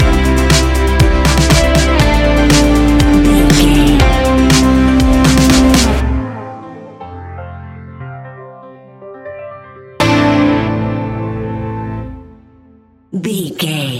Ionian/Major
A♭
ambient
electronic
new age
downtempo
pads